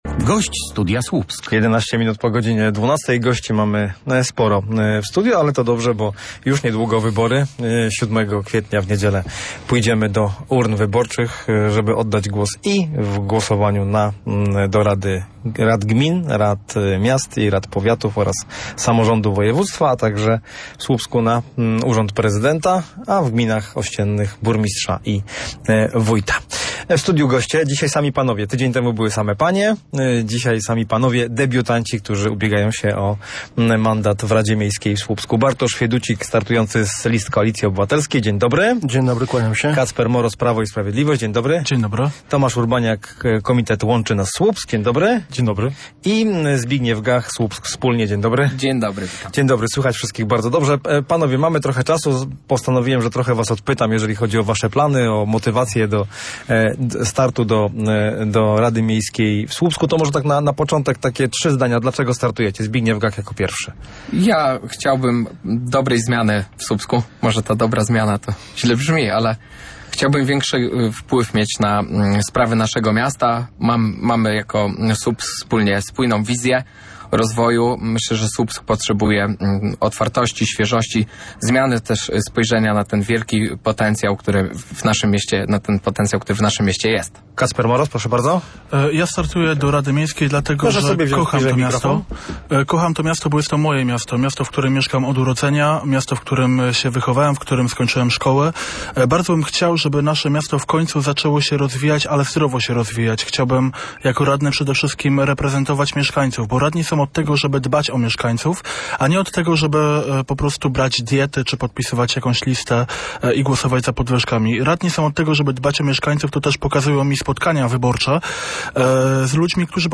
Debata debiutantów. Kandydaci do słupskiej rady miasta o problemach, kadencji i kulturze
Wszyscy to debiutujący w tych wyborach kandydaci do rady miasta.